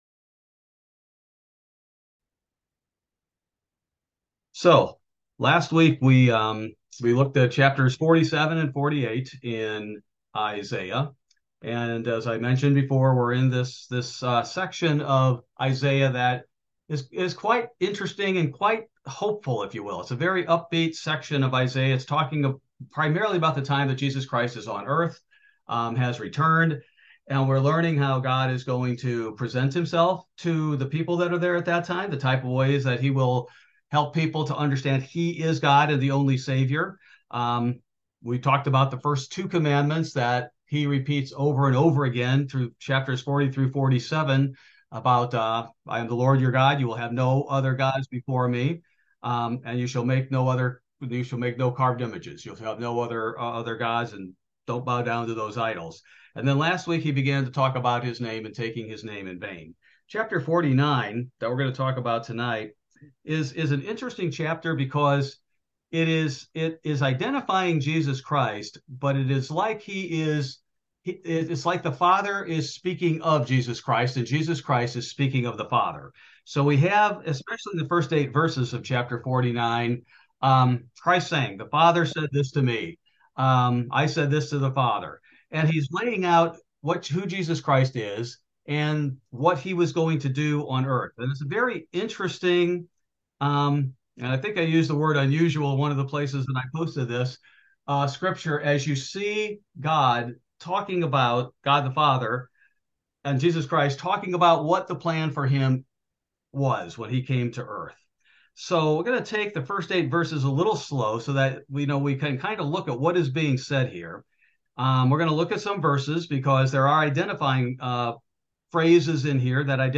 This verse by verse Bible Study primarily covers Isaiah 49 -- YHWH and the One Who Became Christ